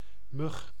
Ääntäminen
IPA: /mʏx/